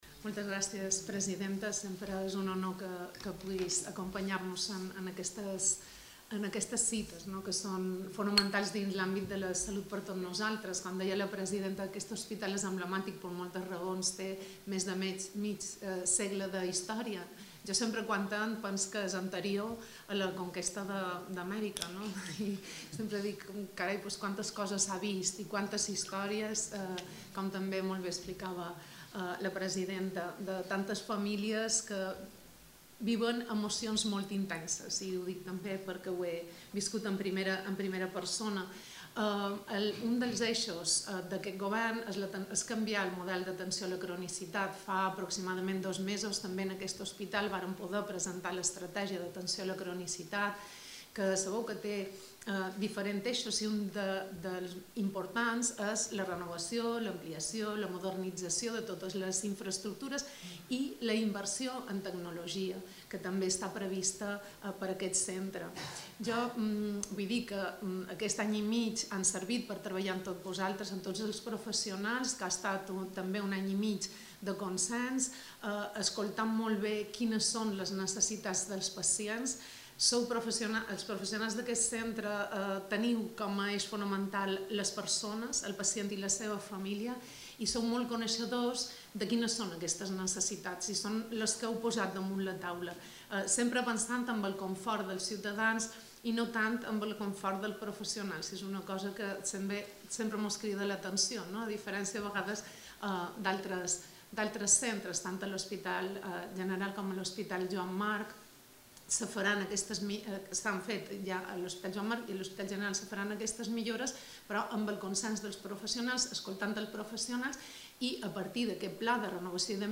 Declaraciones-de-la-consellera-de-Salut-en-el-Hospital-General.mp3